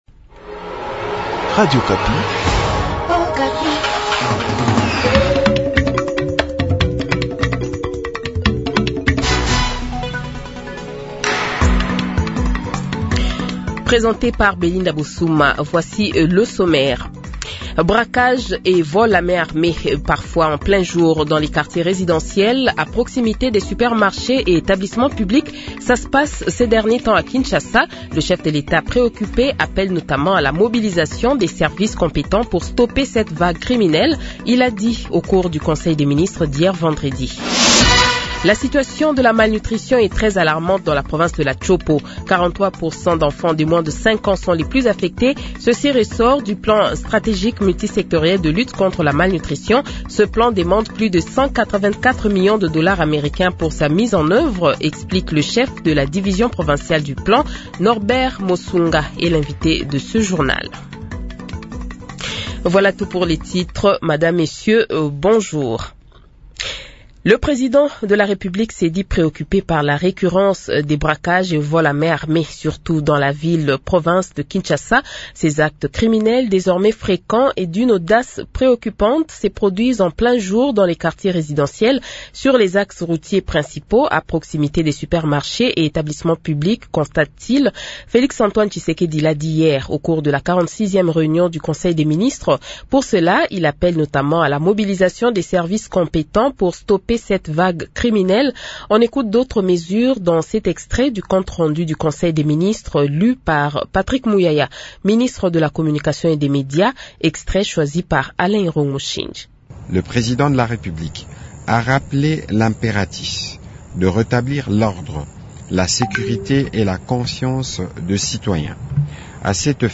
Journal Francais Midi
Le Journal de 12h, 07 Juin 2025 :